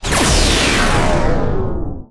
SPACE_WARP_Complex_01_stereo.wav